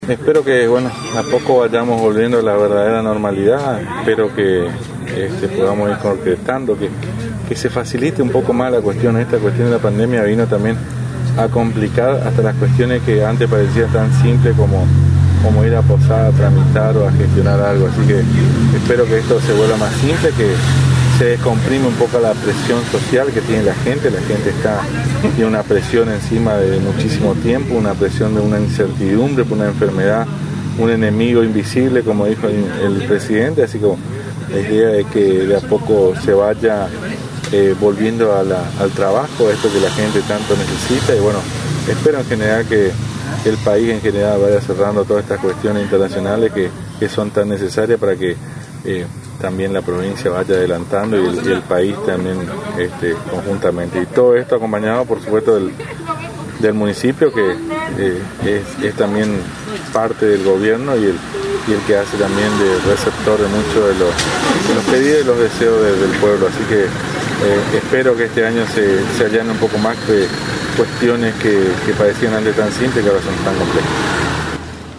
En ocasión del acto de apertura de sesiones ordinarias el HCD de San Ignacio,